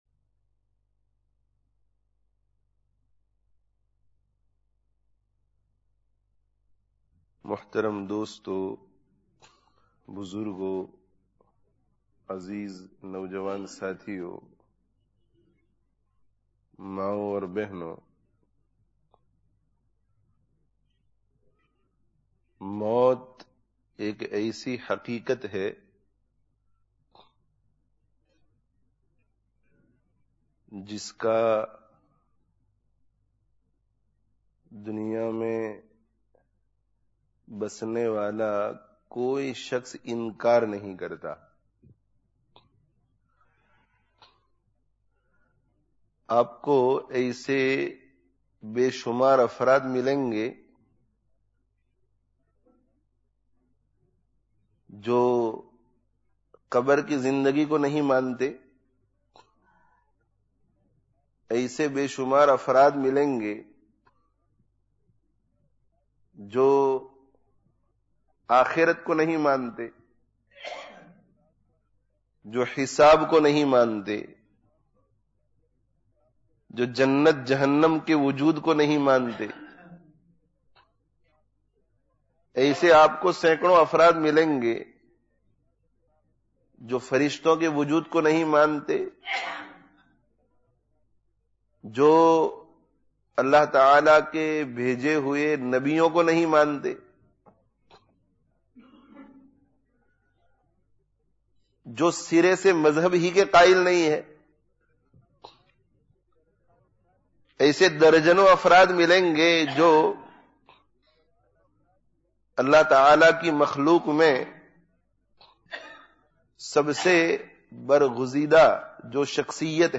Ek Din Marnā hai [Jumu'ah Bayan] (Masjid an Noor, Leicester 28/04/06)